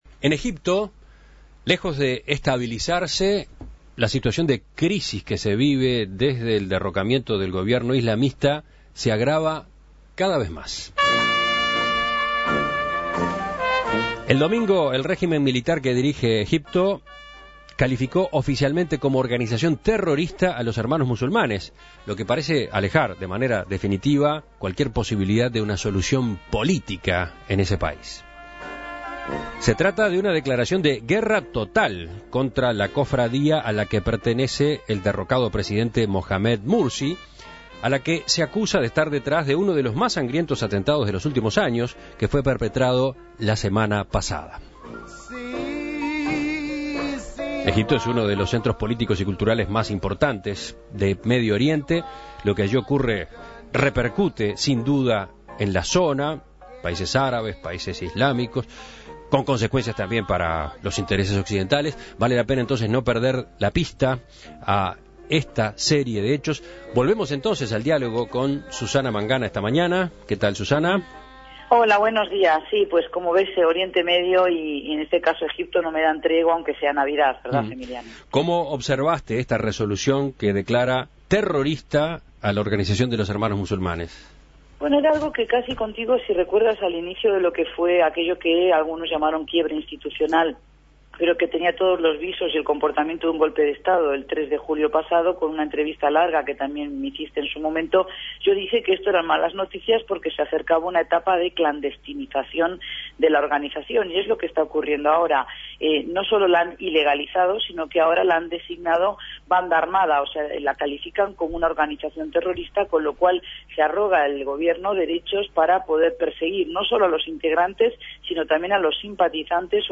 (emitido a las 8.36 Hs.)